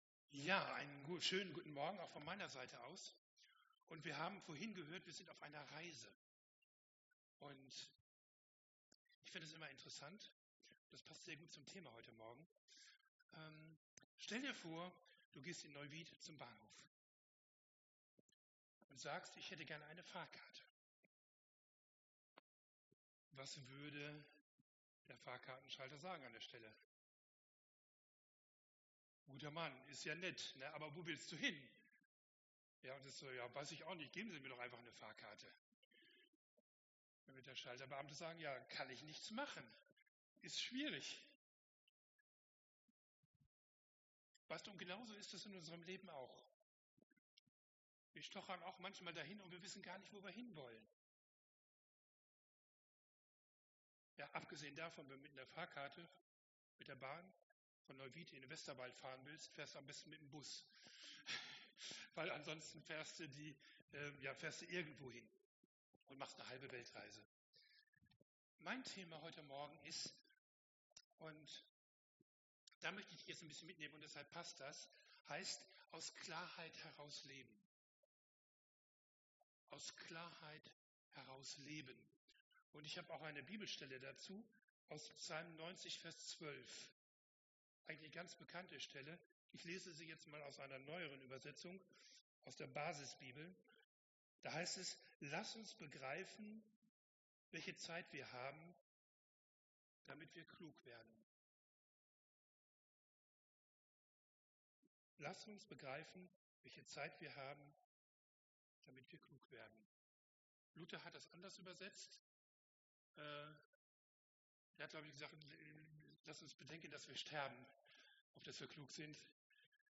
Genre: Predigt.